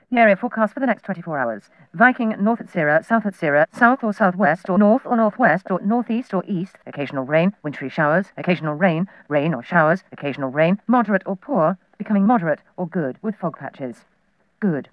Wav World is the home of comedy desktop sounds.
Very Fast Shipping Forecast sound
shipping.wav